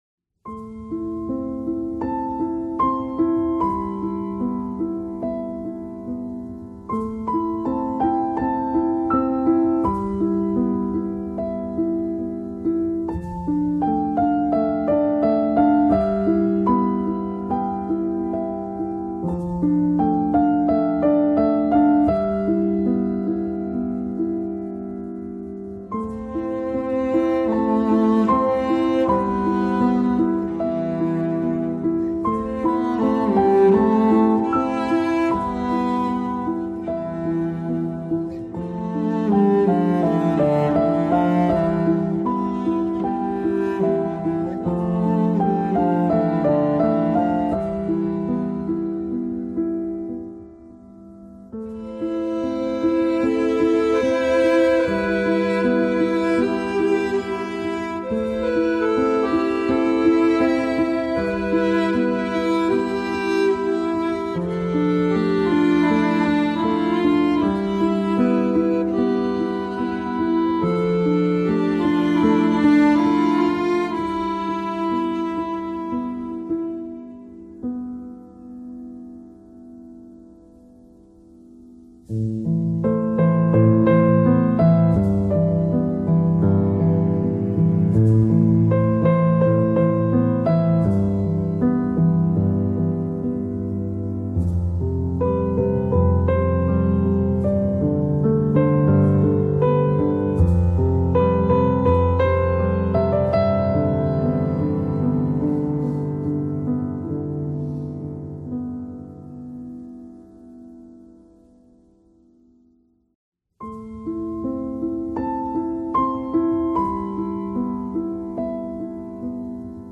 دانلود اهنگ بی کلام